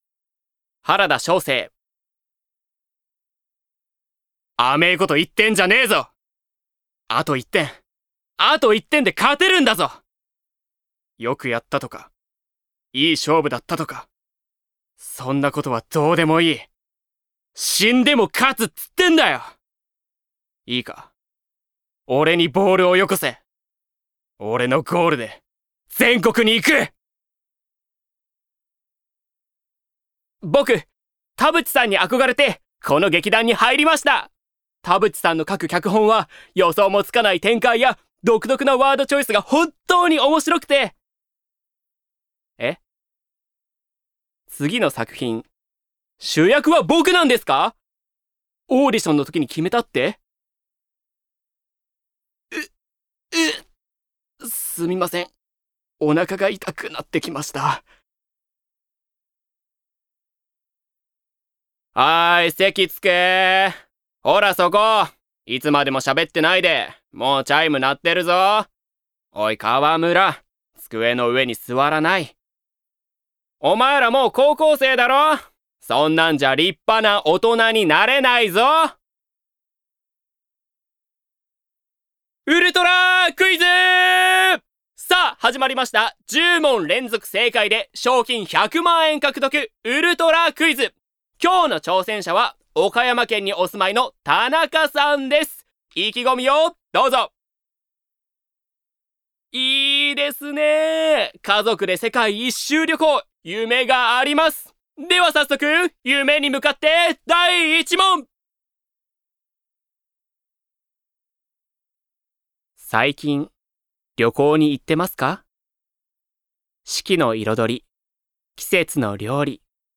誕生日： 10月4日 血液型： A型 身 長： 163cm 出身地： 三重県 趣味・特技： 空手、ボードゲーム 音域： Ａ2～Ｅ4
VOICE SAMPLE